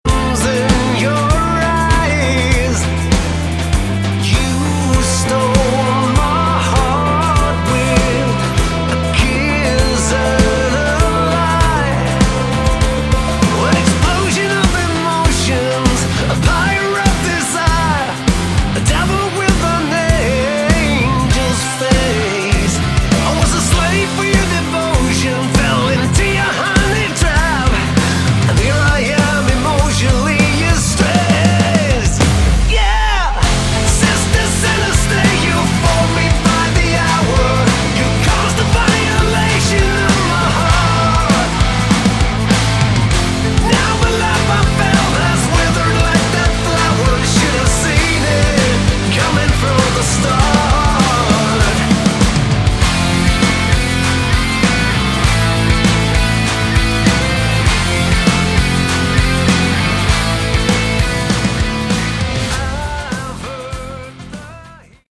Category: Hard Rock / Melodic Metal
guitars, keyboards
bass
drums